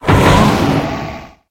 Cri de Gorythmic dans Pokémon HOME.